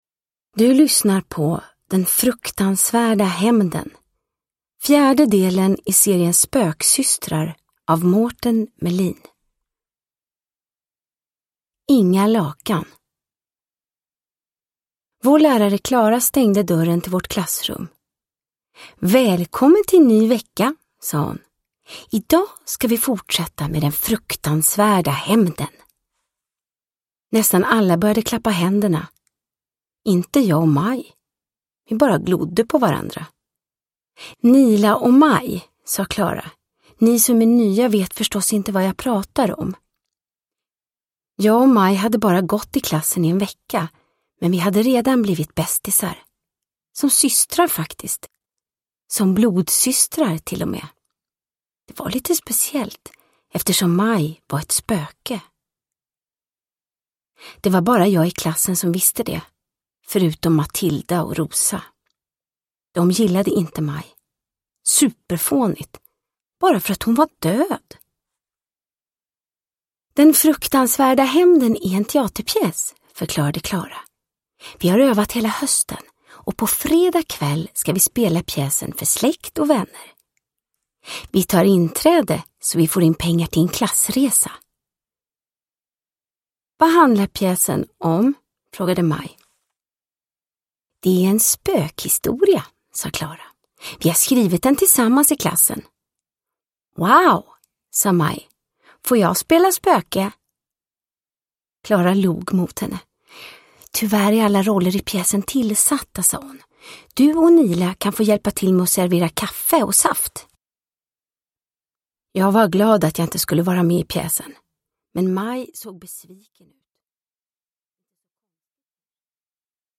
Den fruktansvärda hämnden – Ljudbok – Laddas ner
Uppläsare: Vanna Rosenberg